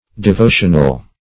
Devotional \De*vo"tion*al\, a. [L. devotionalis.]